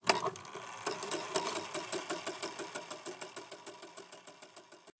Mechanism-winding.ogg